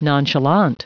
Prononciation du mot nonchalant en anglais (fichier audio)
Prononciation du mot : nonchalant